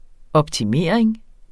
Udtale [ ʌbtiˈmeˀɐ̯eŋ ]